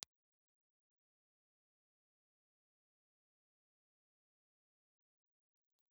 Impulse Response file of the STC 4031J Ball and Biscuit microphone, perpendicular to sound source
STC_4021_Ball_Biscuit_Vertical.wav
Impulse response files have been supplied with the microphone positioned horizontally (on axis) and vertically to the source.